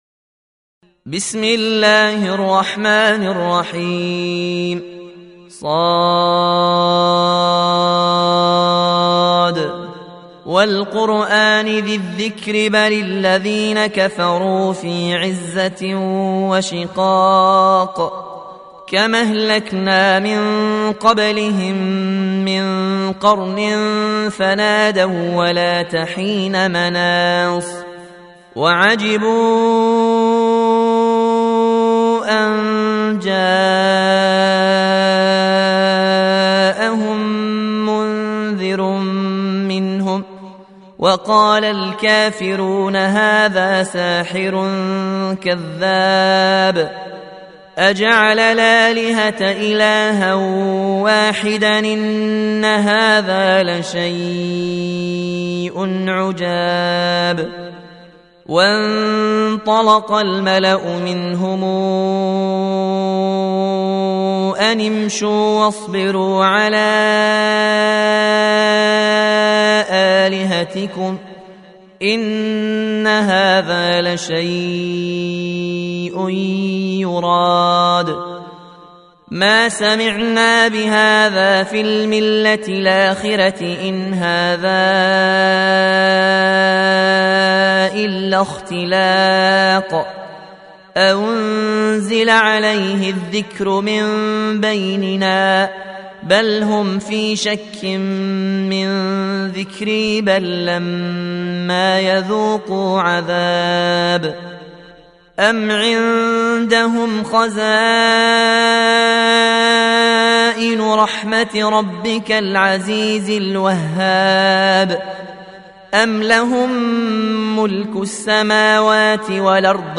Surah Sequence تتابع السورة Download Surah حمّل السورة Reciting Murattalah Audio for 38. Surah S�d. سورة ص N.B *Surah Includes Al-Basmalah Reciters Sequents تتابع التلاوات Reciters Repeats تكرار التلاوات